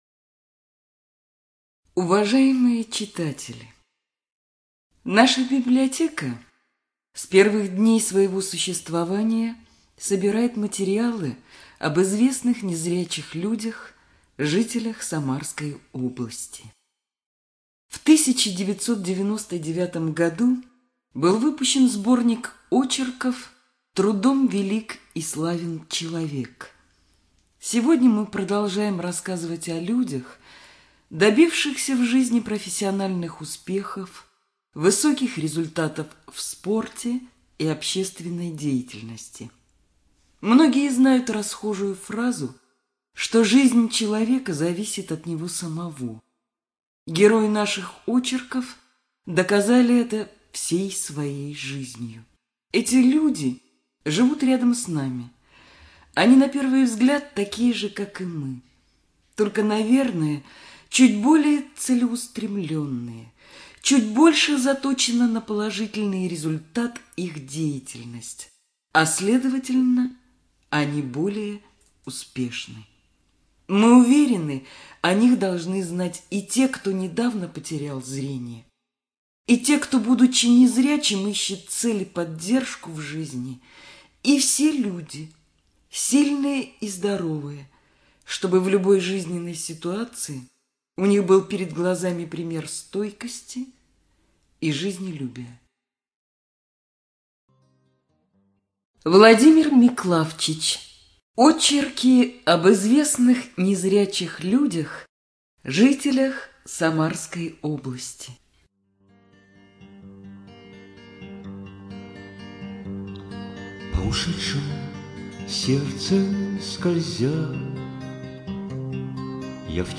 Студия звукозаписиСамарская областная библиотека для слепых